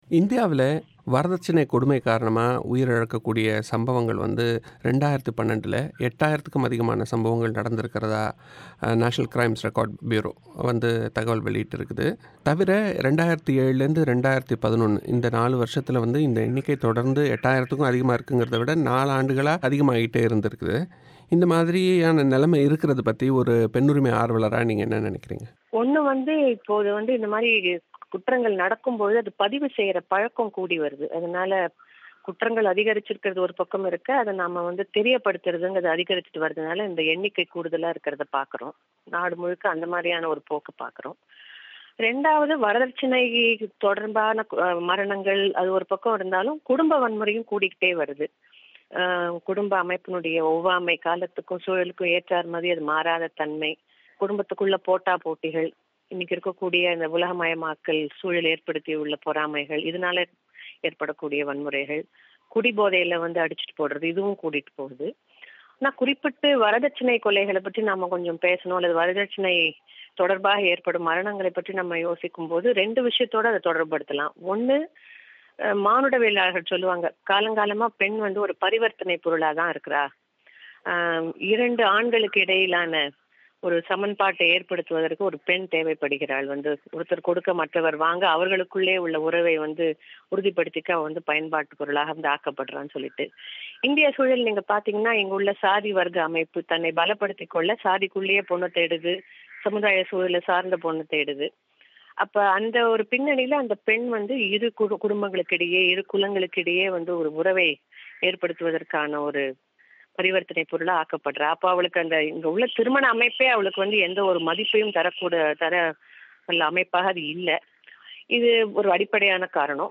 செவ்வி